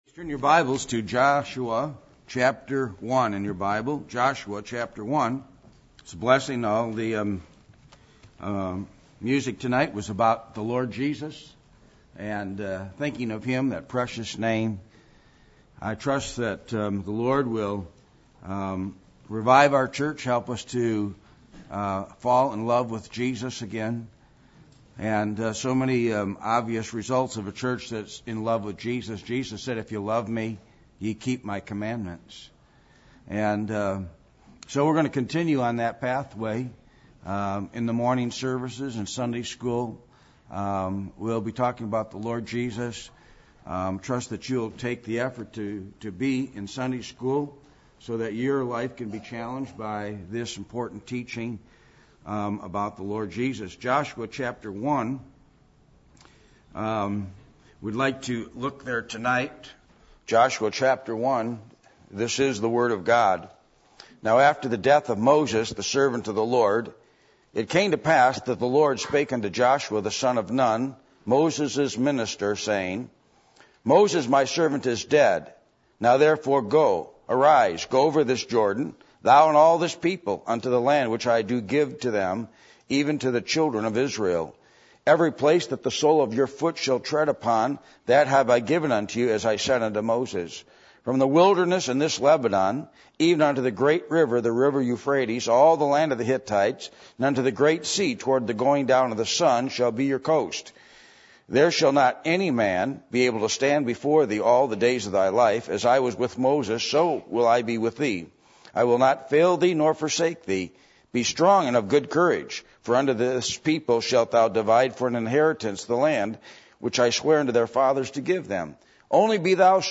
Joshua 1:1-9 Service Type: Sunday Evening %todo_render% « What Does The Name Of Jesus Mean To You?